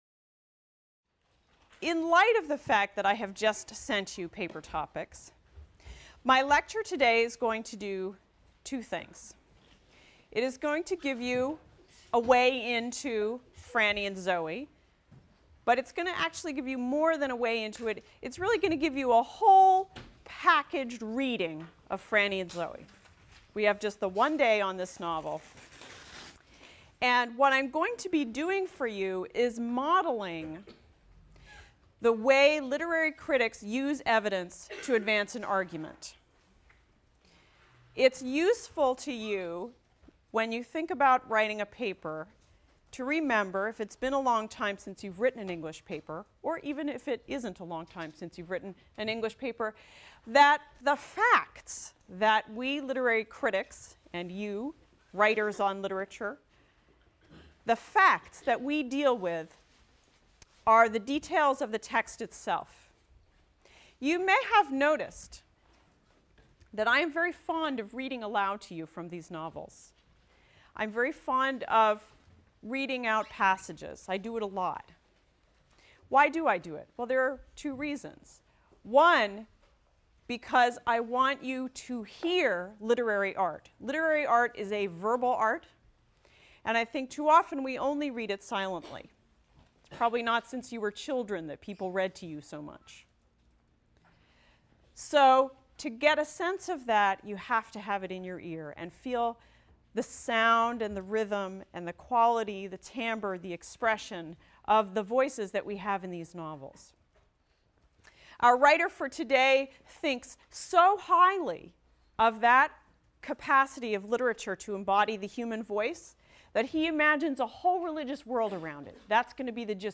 ENGL 291 - Lecture 10 - J. D. Salinger, Franny and Zooey | Open Yale Courses